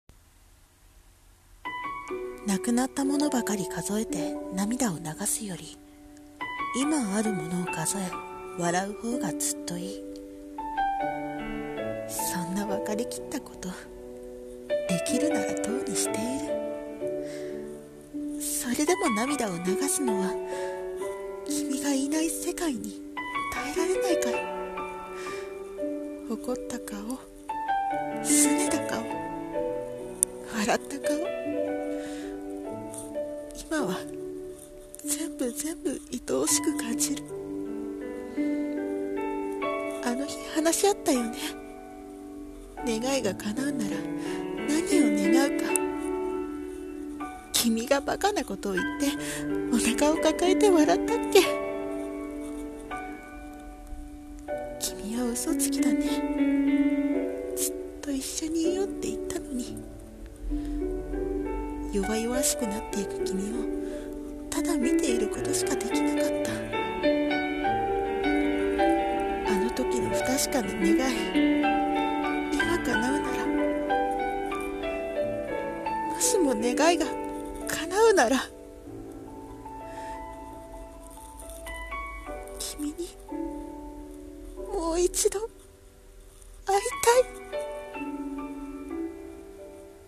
【声劇】もしも願いが叶うなら